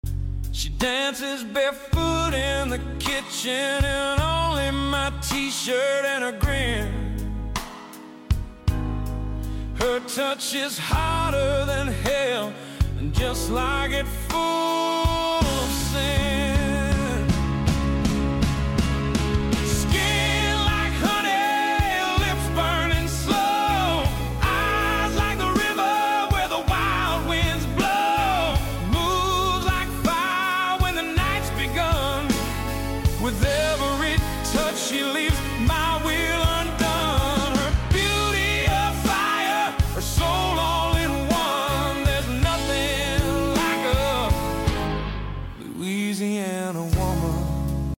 soulful, sensual country song